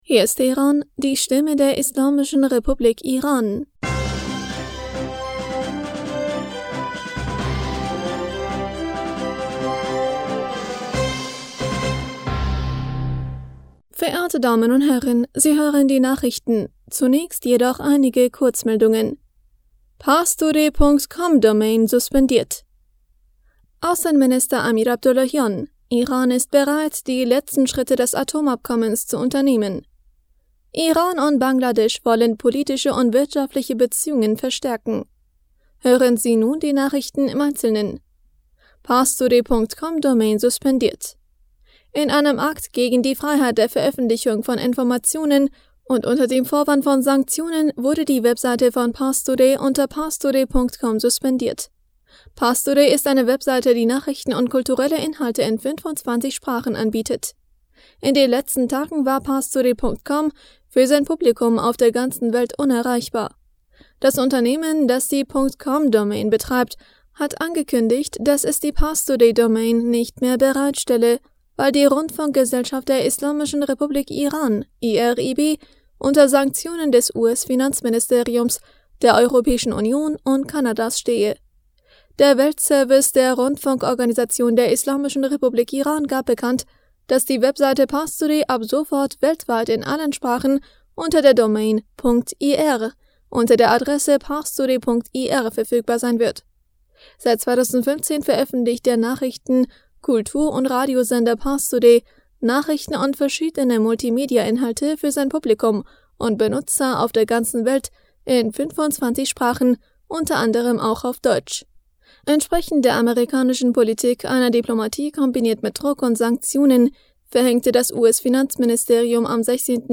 Nachrichten vom 24. Dezember 2022